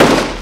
Gun Shot (Criminal).wav